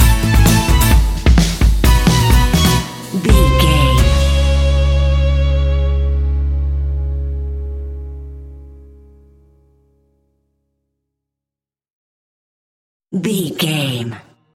Uplifting
Aeolian/Minor
C#
earthy
acoustic guitar
mandolin
ukulele
lapsteel
drums
double bass
accordion